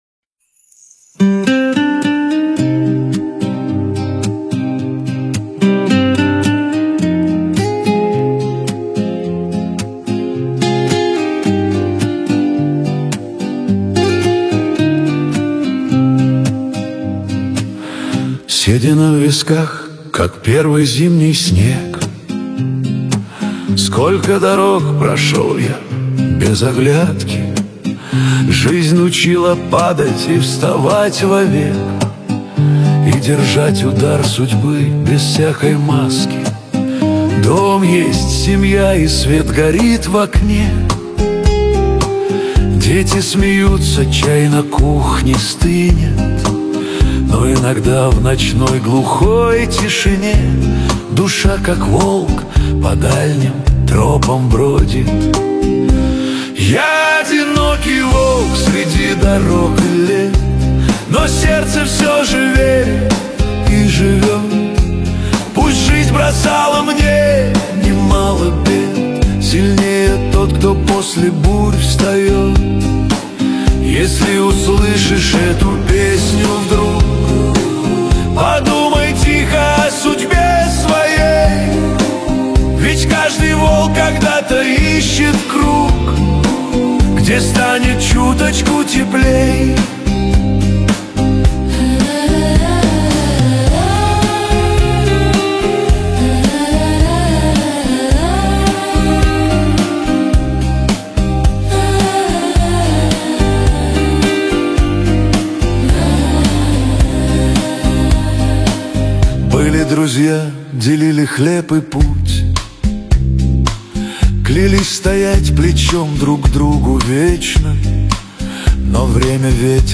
Душевная песня о жизни со смыслом
Шансон который заставляет задуматься